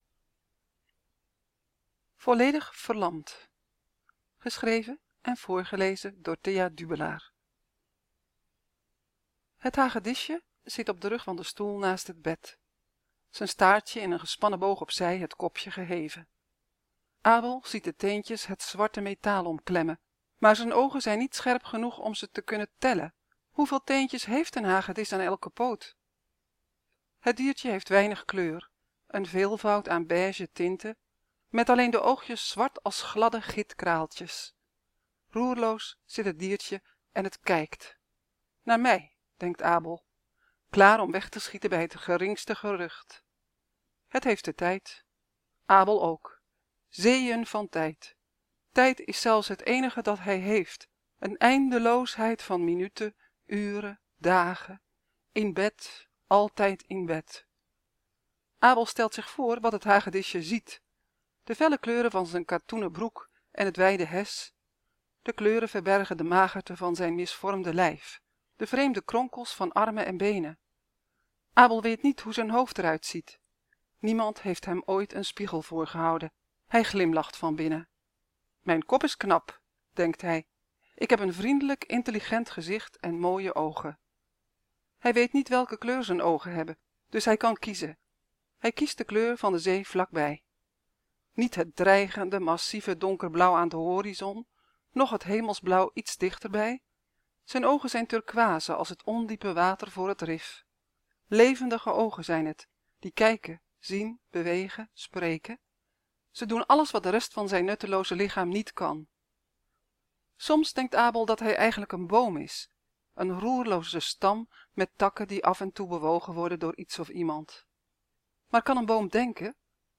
Ik lees elke dag een verhaal voor uit de bundel ‘Vliegeren’. Vandaag een verhaal over Abel die volledig verlamd op bed ligt op een tropisch eiland (voor 12 jaar en ouder) Zorg dat je geluid aan staat en klik op de play knop.